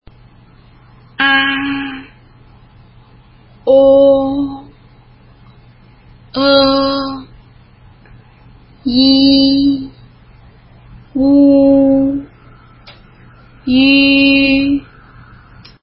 単母音
a 口を大きく開けてしっかり息をはき出すように｢アー｣と発音する。
o 唇を丸く少し前に突き出して、のどの奥から｢オー｣と発音する。
e 日本語の｢エ｣の口で｢オー｣と発音する。
i 唇を十分横に引いて｢イー｣と発音する。
u 日本語の｢ウ｣の口より唇を丸く突き出して喉の奥から｢ウー｣と発音する。
ü 日本語の｢ウ｣の口の形で｢ユィ｣と発音する。